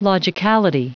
Prononciation du mot : logicality